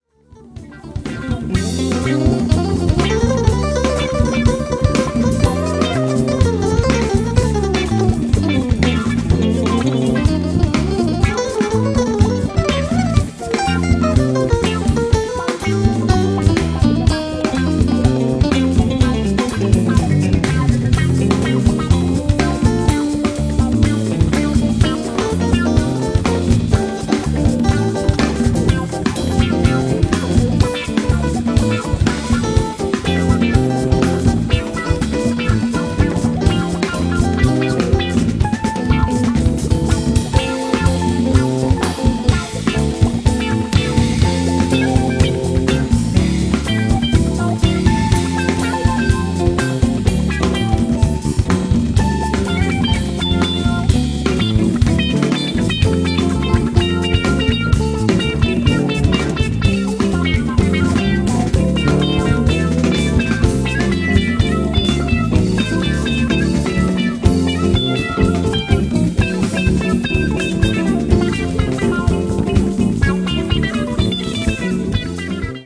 In short, it grooves as it moves.